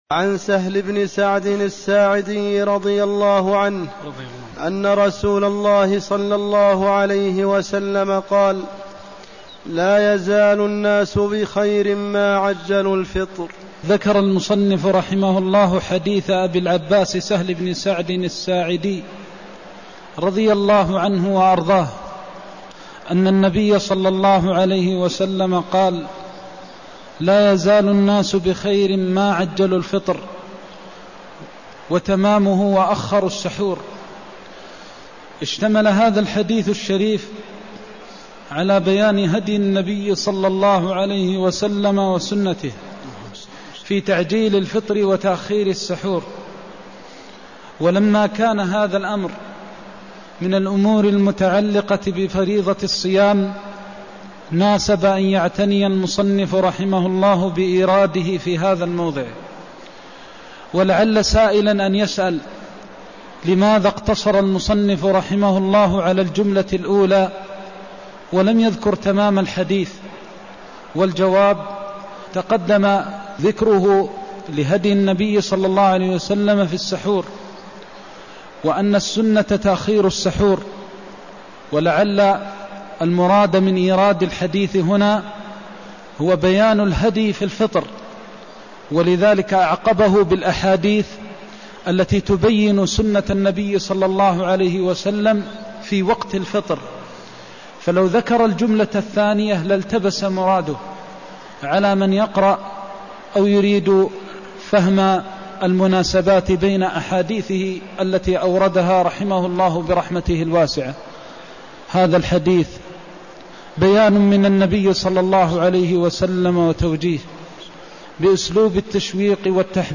المكان: المسجد النبوي الشيخ: فضيلة الشيخ د. محمد بن محمد المختار فضيلة الشيخ د. محمد بن محمد المختار لا يزال الناس بخير ما عجلوا الفطر وأخروا السحور (185)